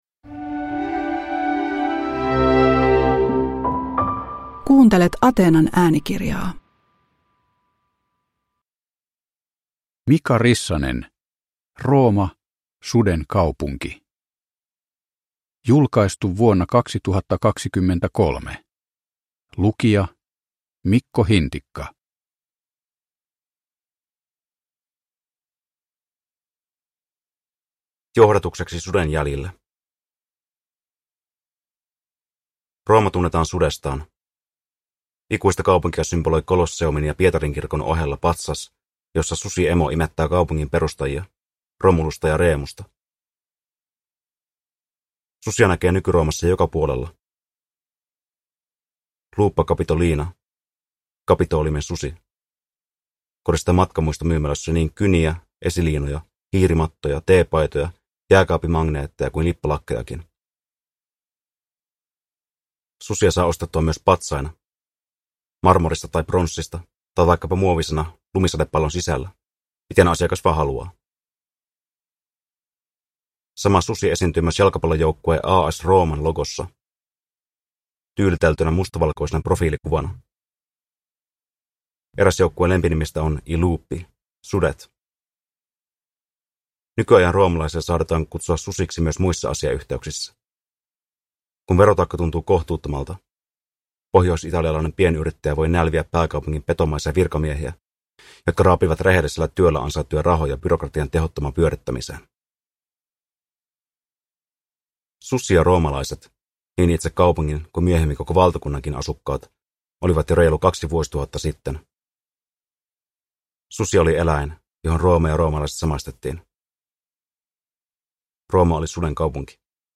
Rooma, Suden kaupunki – Ljudbok